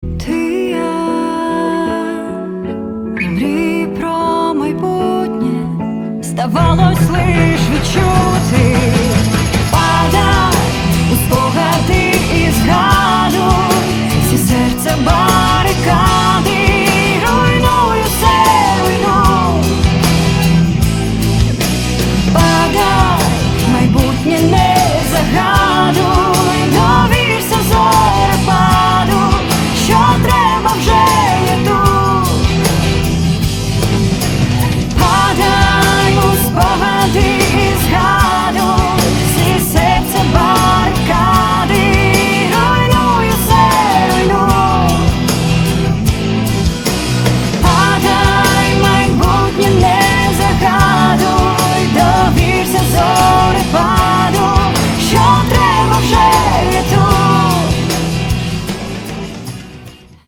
• Категория: Рок рингтоны